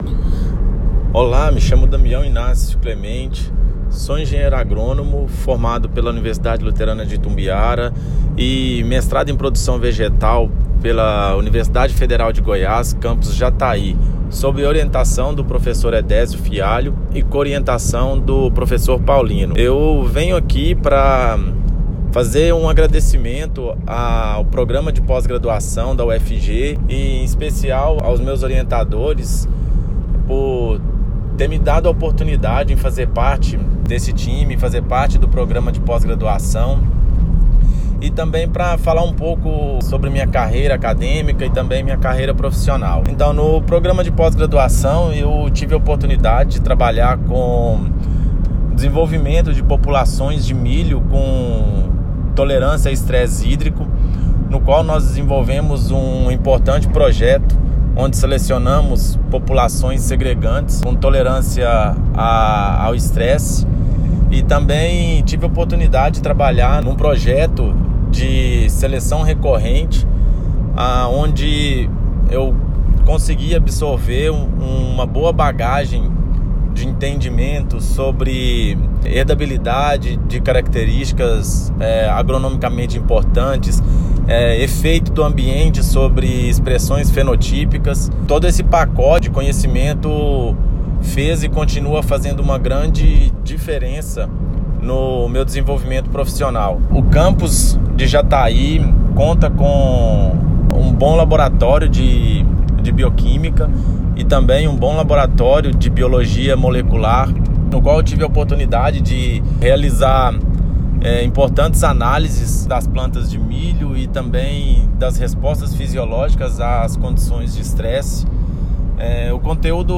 Depoimento de Egressos - PPGA